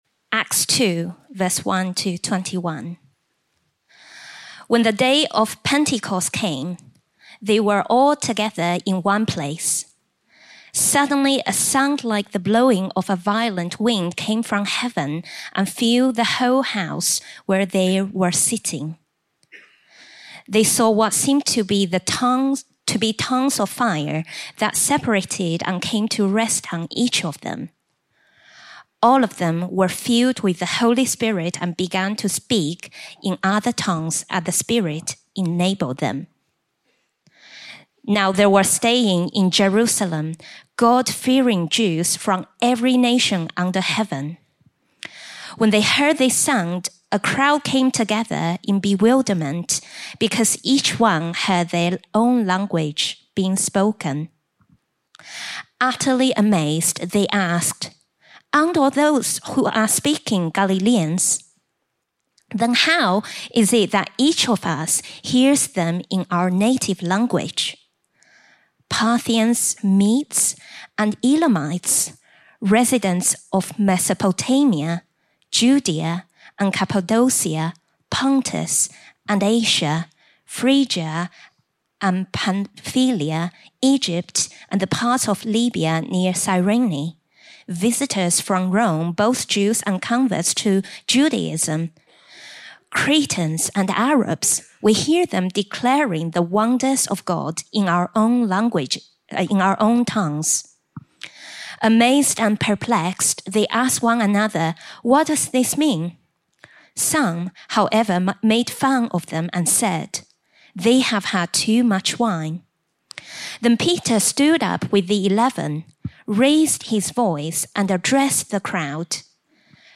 Series: Leaders' Conference 2025